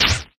max_dryfire_01.ogg